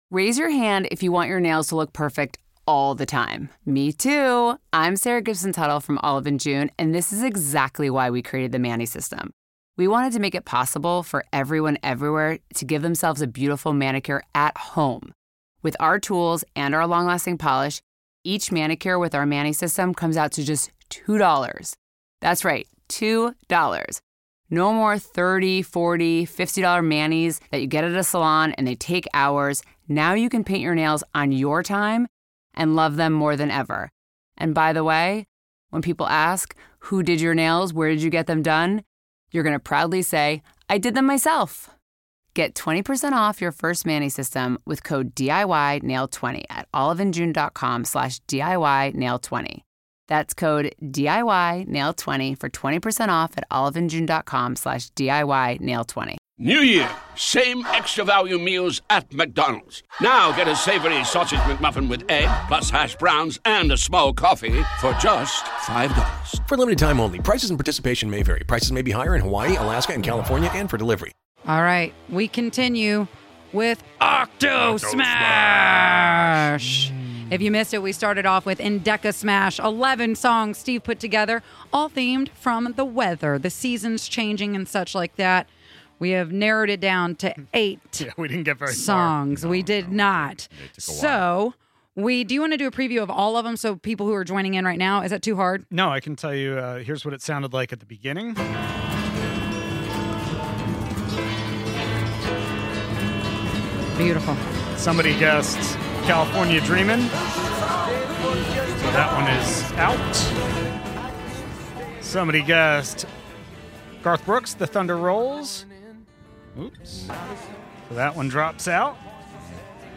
Can you hear one song, two songs, or more?!